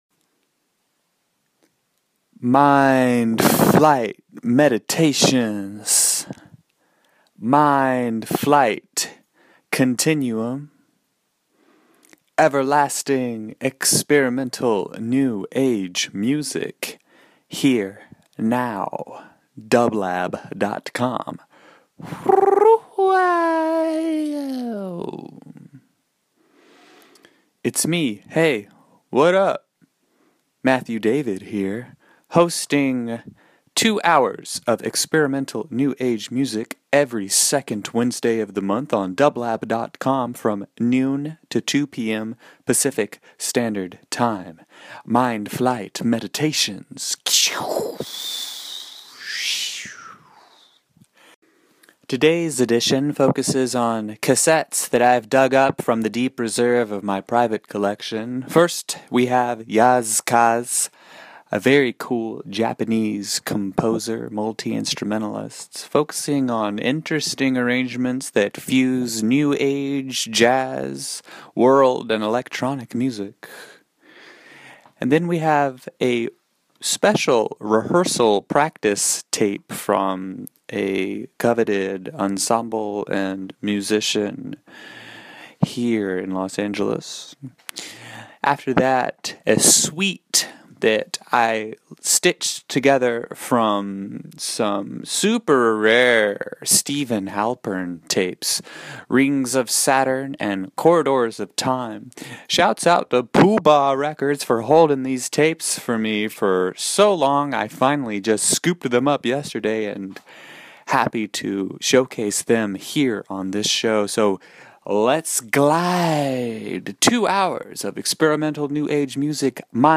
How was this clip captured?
featuring lost tapes and live improvisations.